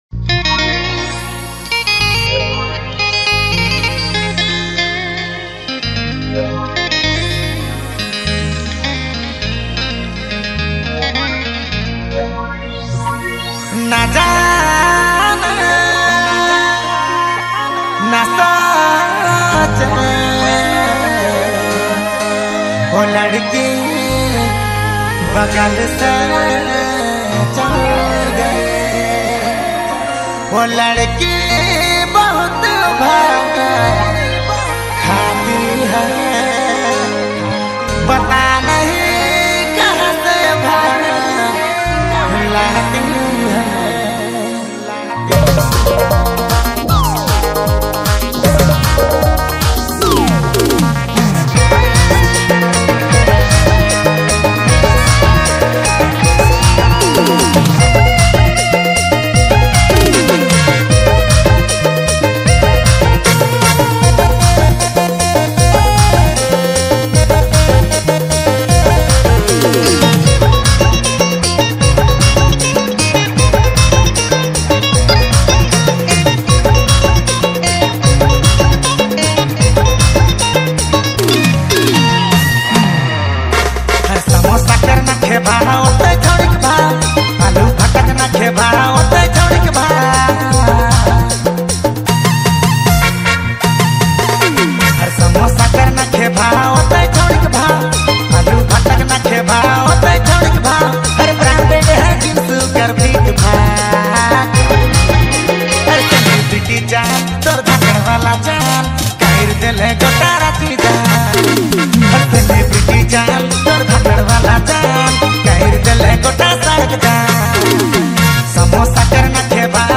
Groove to the catchy beats